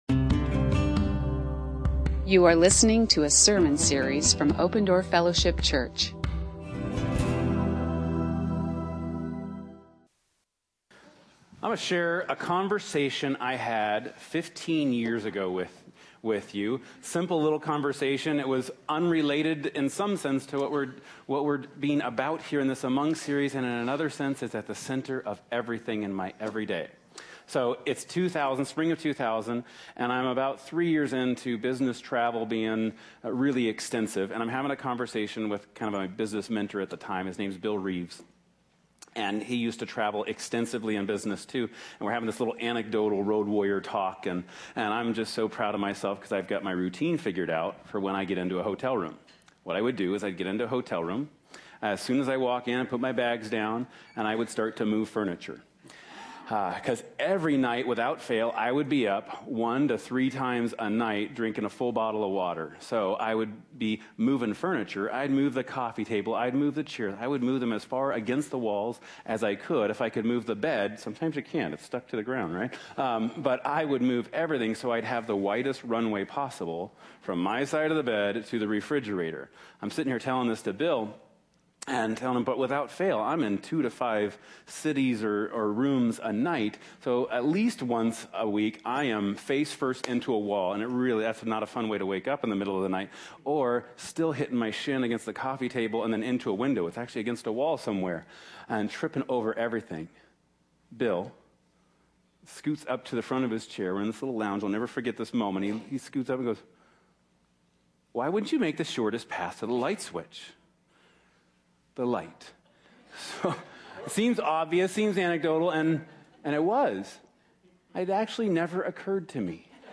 You are listening to an audio recording of Open Door Fellowship Church in Phoenix, Arizona.